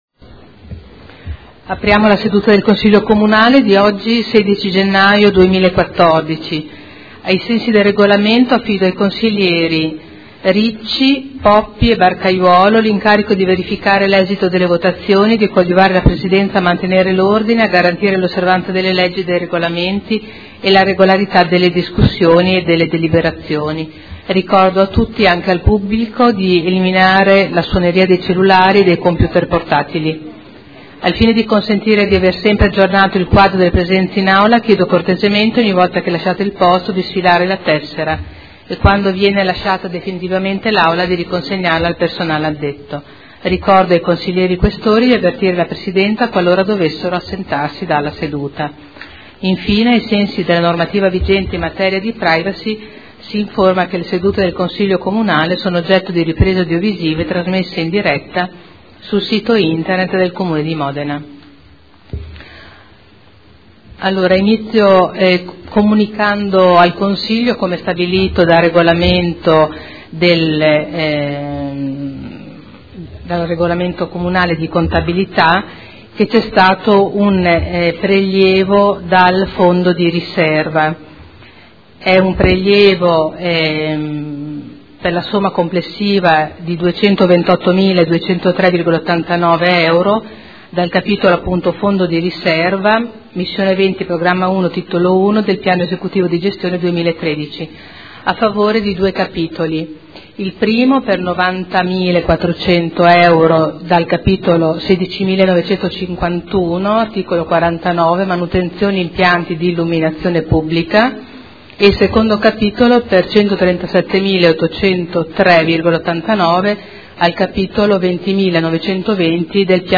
Seduta del 16 gennaio. Apertura del Consiglio Comunale e comunicazione del Presidente sul fondo di riserva